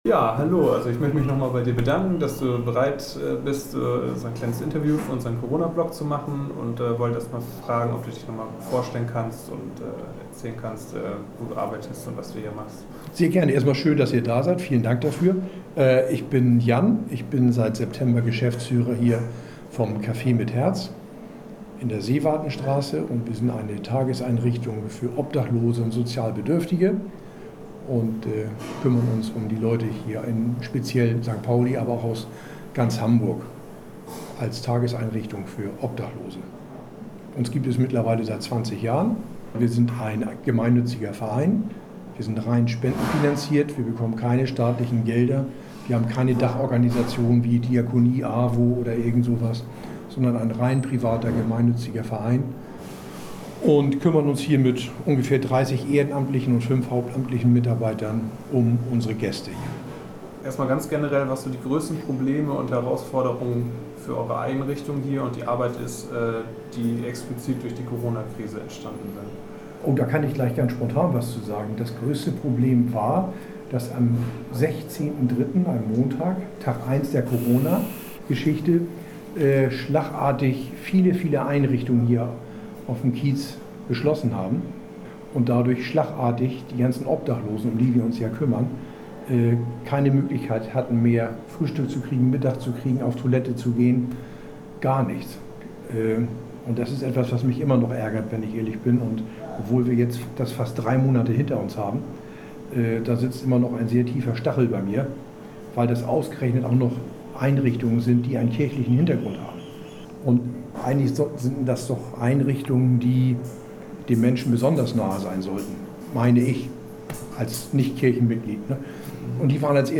Interview_KaffeeMitHerz_Cut_mp3.mp3